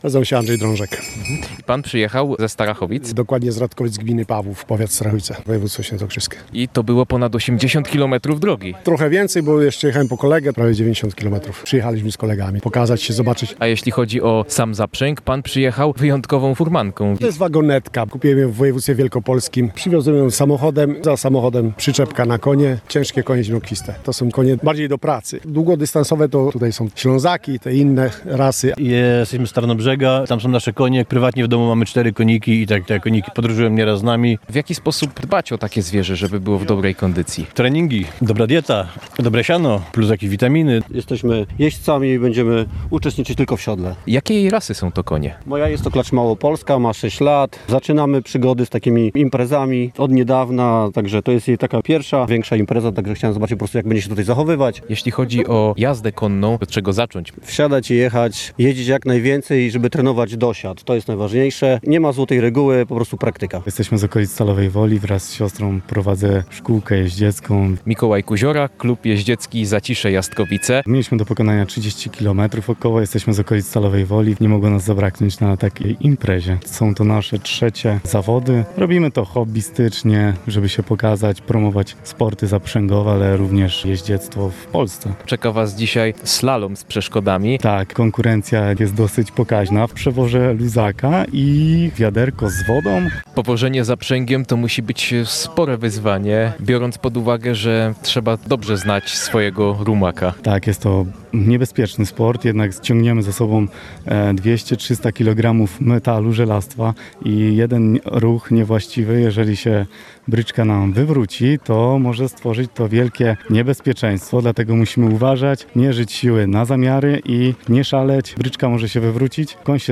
Zlot-Furmanek-w-Furmanach.mp3